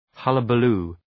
Προφορά
{‘hʌləbə,lu:}